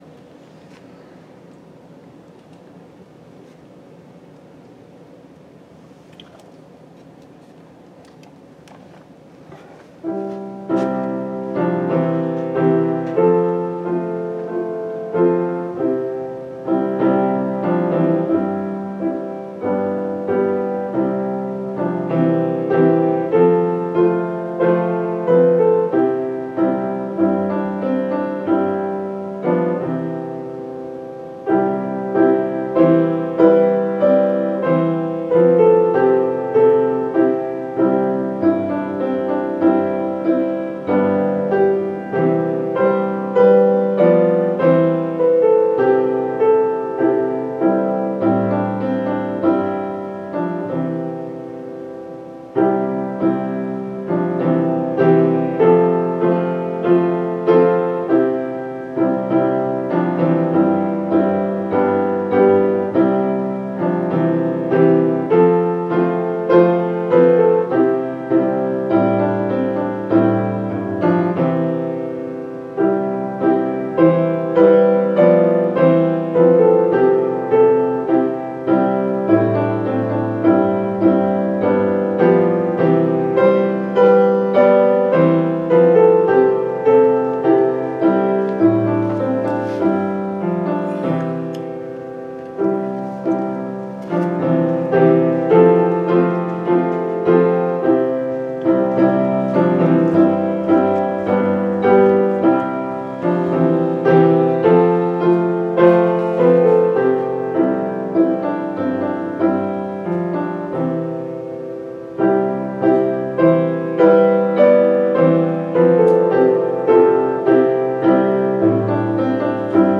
May 19, 2019 Service